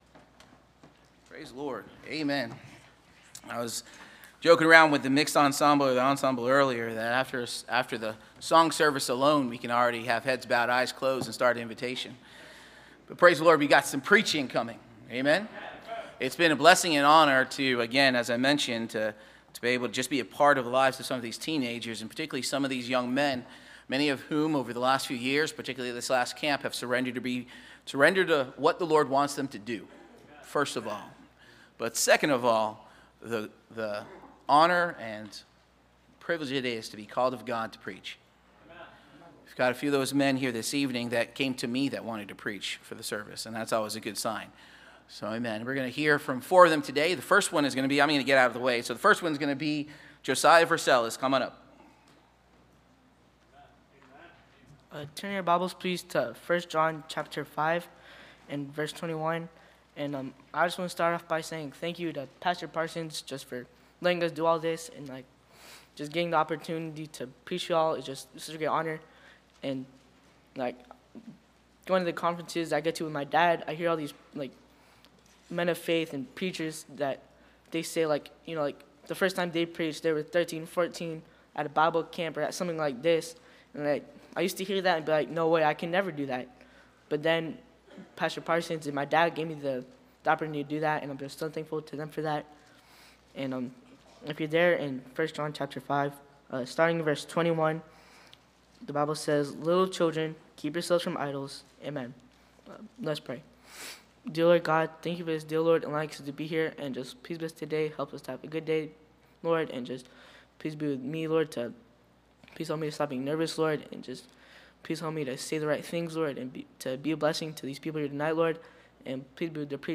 Teen Service – Landmark Baptist Church
Service Type: Sunday Evening Teens